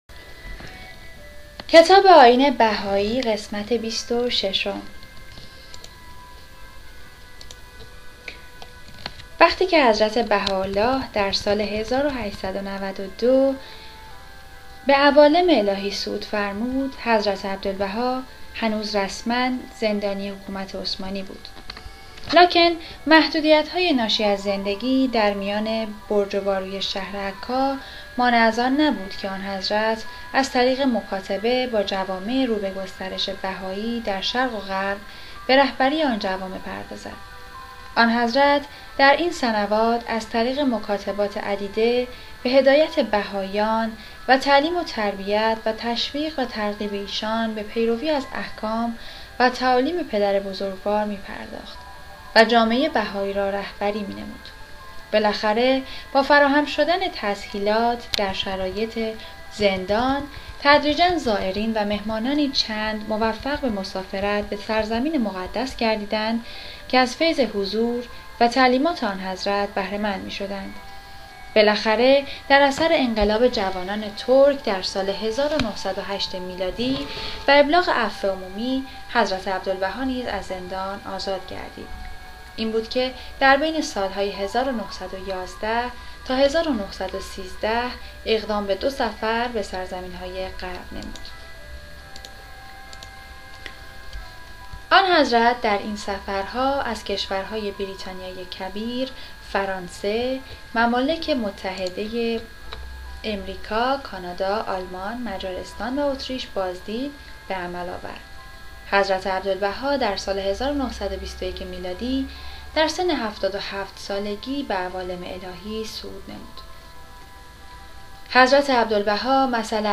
کتابهای صوتی